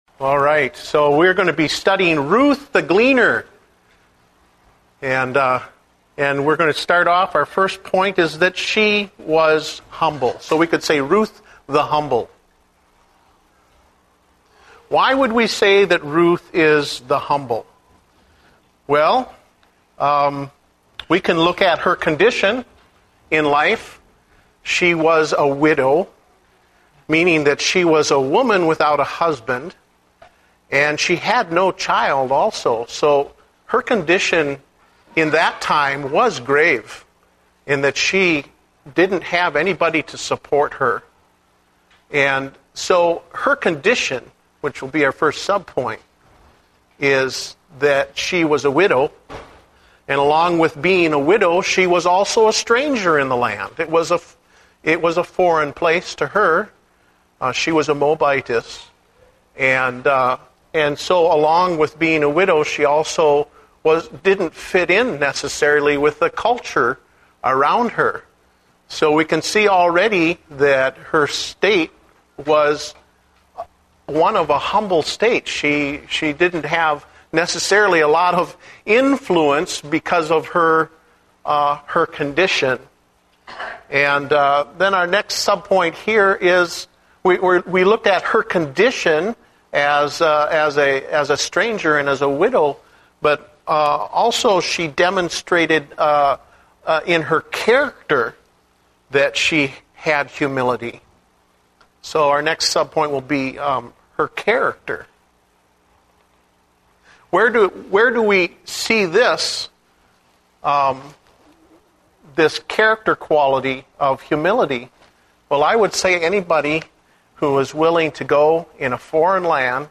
Date: July 11, 2010 (Adult Sunday School)